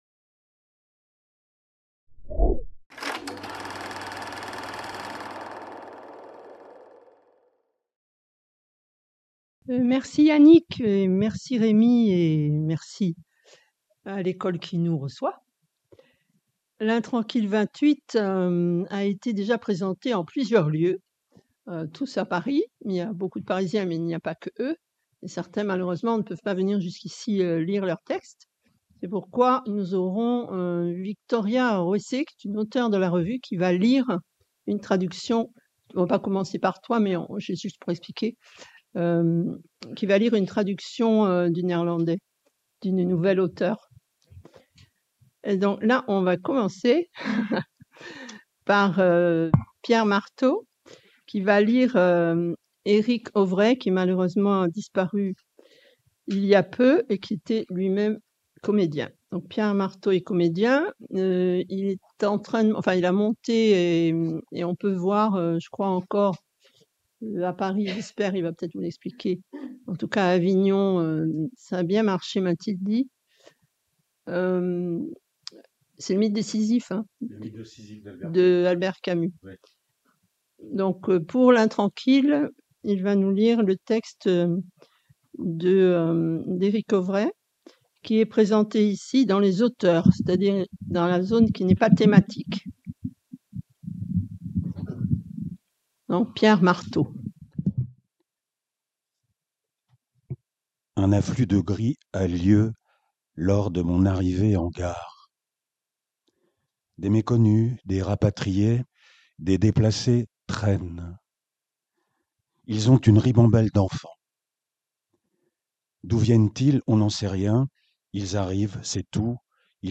Soirée Ent'revues : Lectures et performances autour du n° 28 de L'Intranquille | Canal U